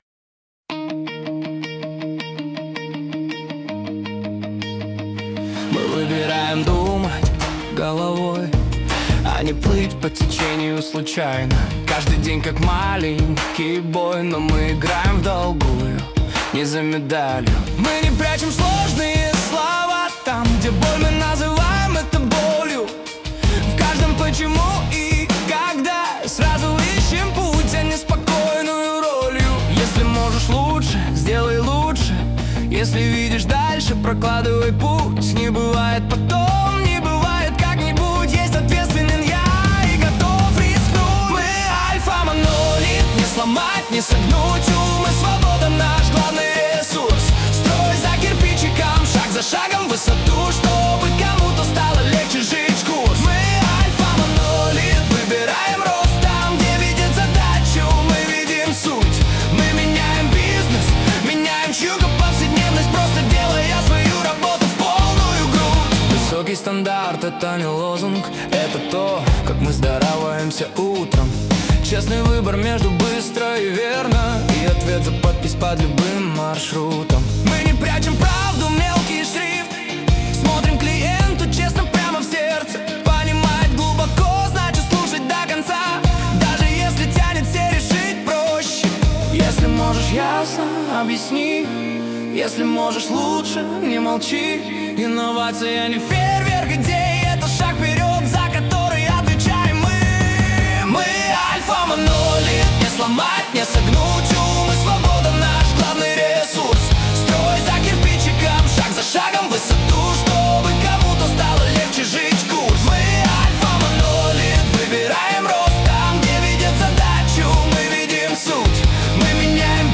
Вдохновляя на результат: «Альфамонолит» презентует корпоративный гимн.